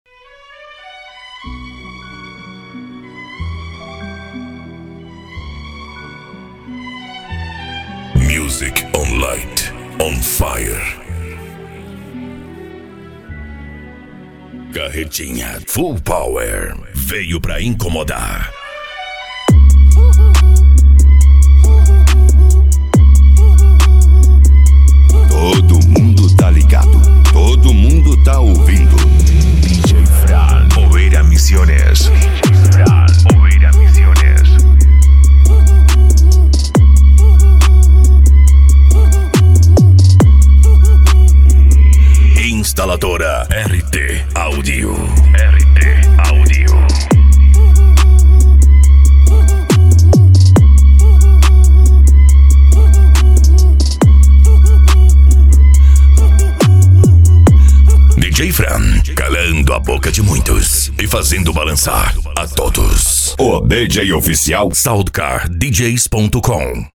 Bass
Remix
Musica Electronica